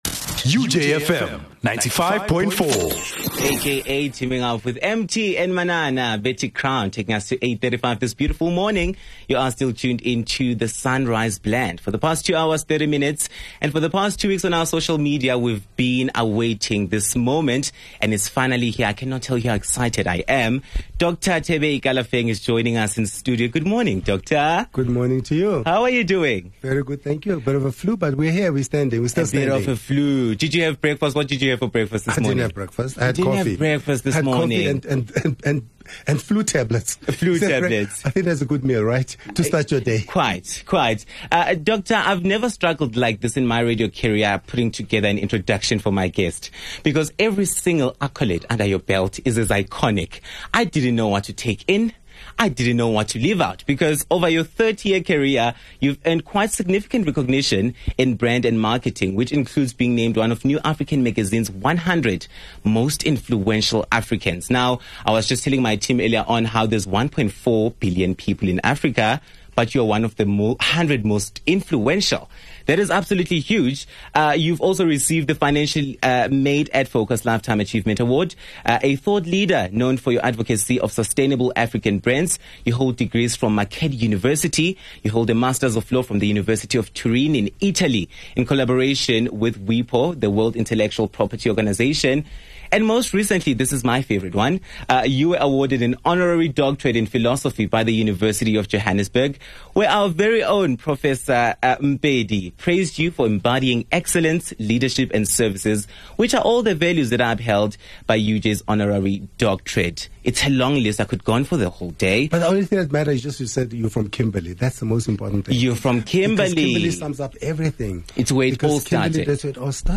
joins us in the studio